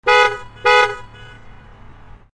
bus-horn.mp3